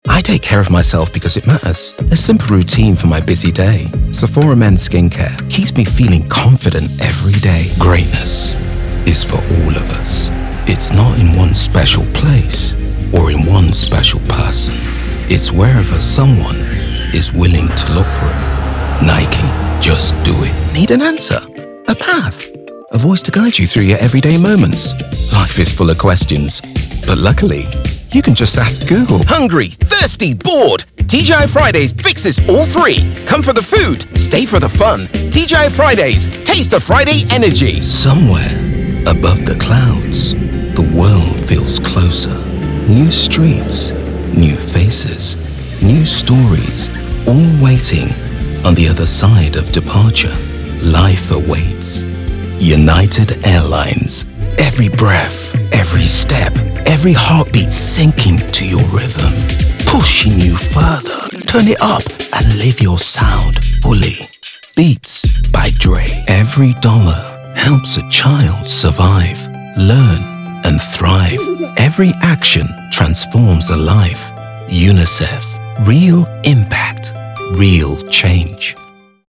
Download the Commercial (British / Global) Voice Demo
Commercial (British / Global)